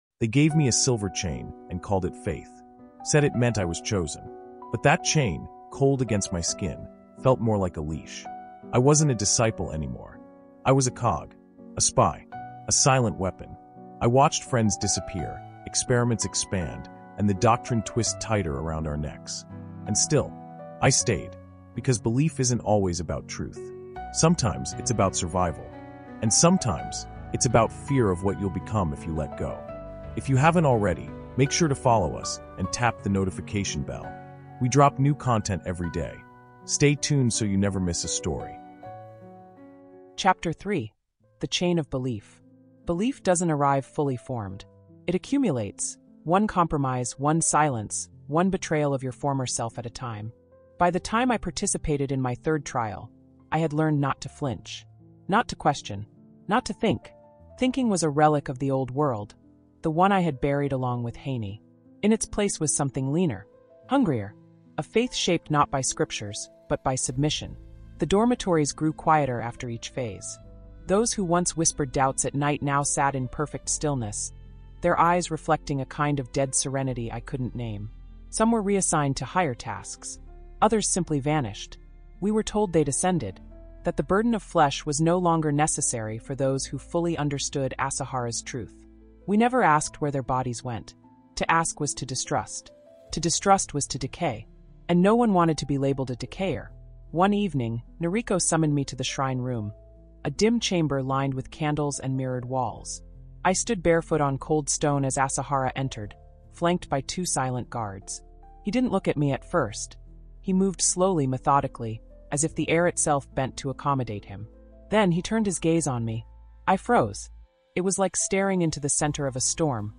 Terror in Tokyo Subway Chapter Three: The Chain of Belief | Audiobook